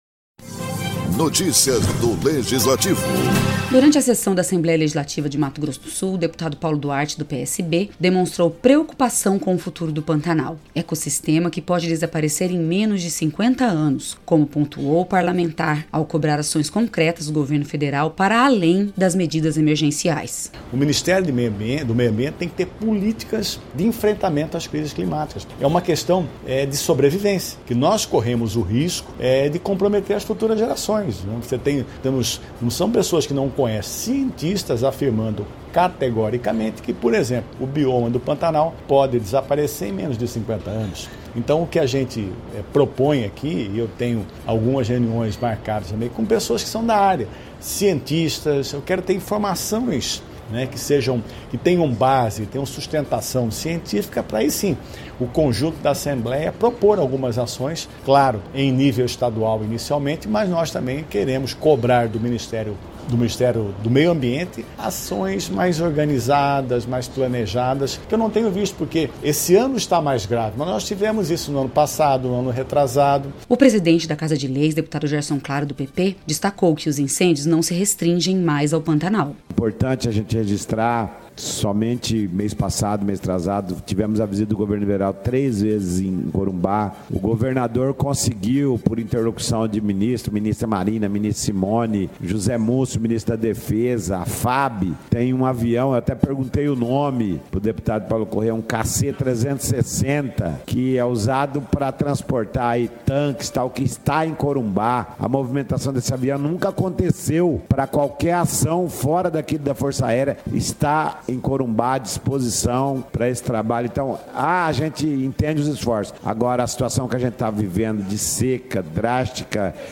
Durante a sessão da Assembleia Legislativa de Mato Grosso do Sul, o deputado Paulo Duarte do PSB demonstrou preocupação com o futuro do Pantanal, ecossistema que pode desaparecer em menos de 50 anos.